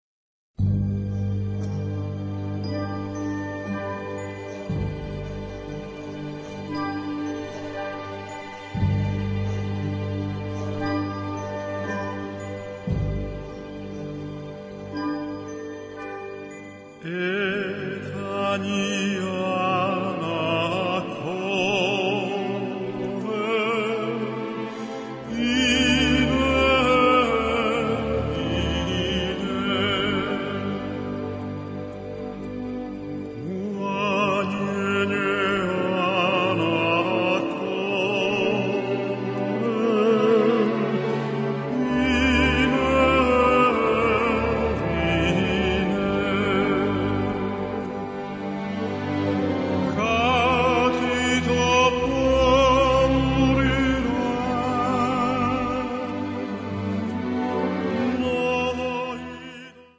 - Timp. - Perc. - Harp - Choir - Strings length
key: G-minor